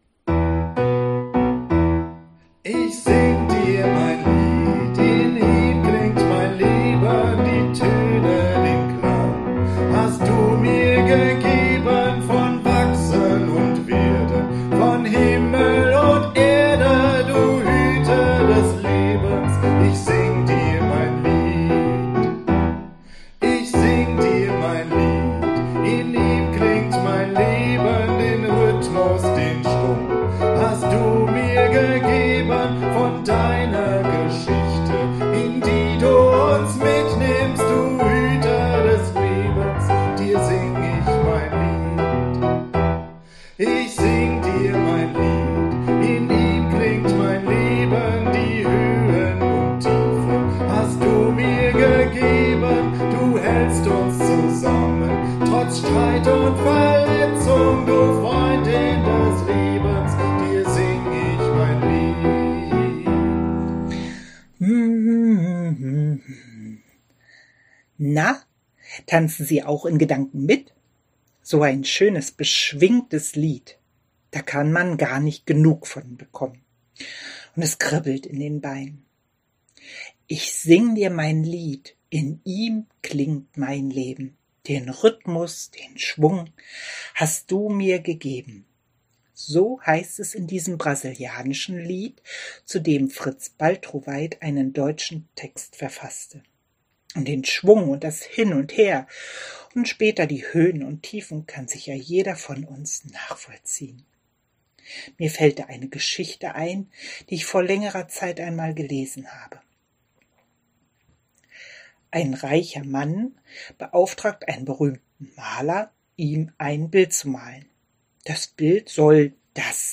Andacht für den 7. Juni